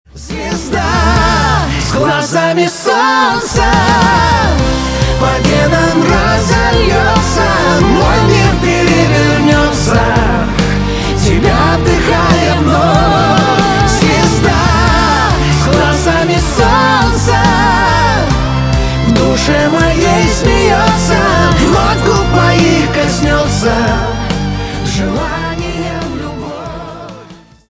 Шансон рингтоны
Громкие рингтоны